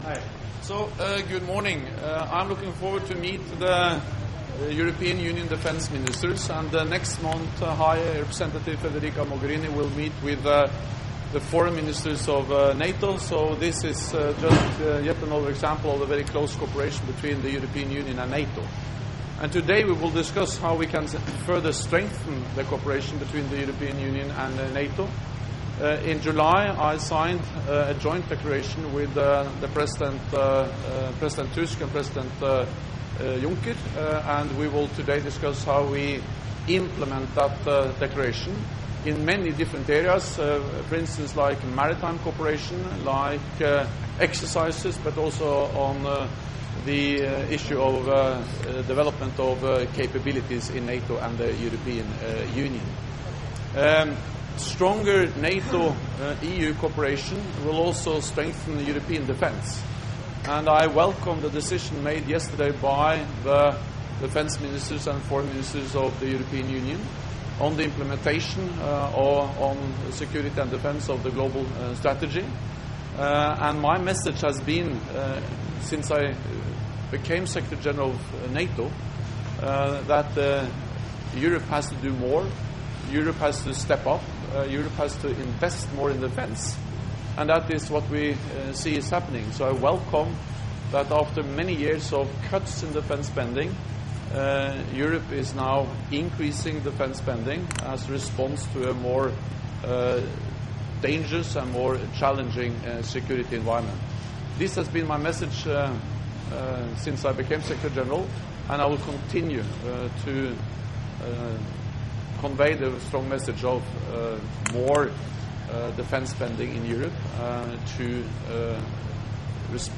Doorstep statement
by NATO Secretary General Jens Stoltenberg at the Council of the European Union